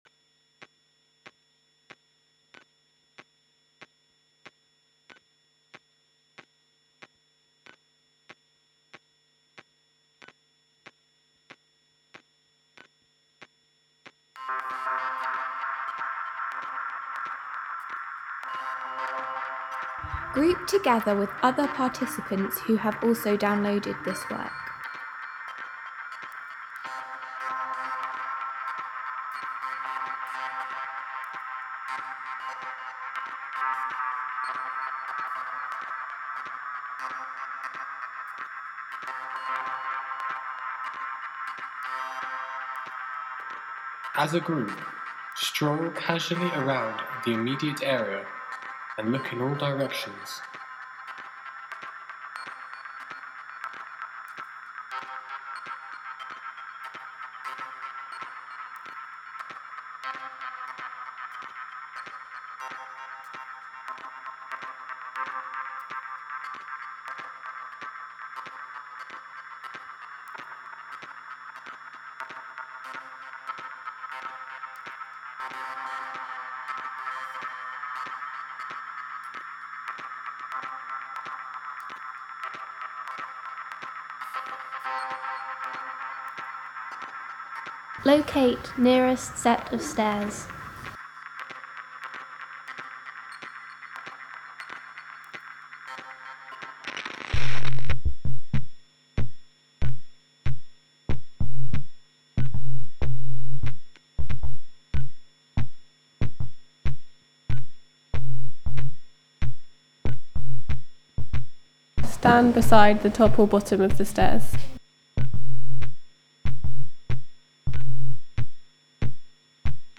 Within this work are spoken instructions in the form of different voice-overs that asks participants to perform gestures/actions in response to how current policies involving the privatisation of spaces contradict democracy, equality, social and environmental justice.